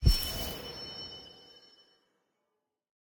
Minecraft Version Minecraft Version 1.21.5 Latest Release | Latest Snapshot 1.21.5 / assets / minecraft / sounds / block / trial_spawner / spawn_item_begin3.ogg Compare With Compare With Latest Release | Latest Snapshot
spawn_item_begin3.ogg